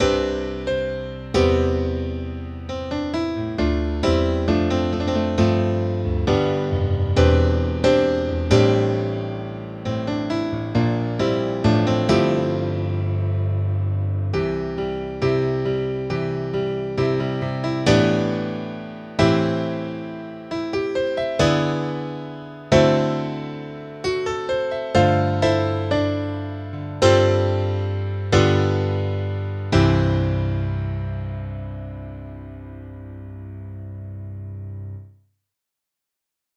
Csak érdekességként,lebutítva 178MB/5 layer-en így szól: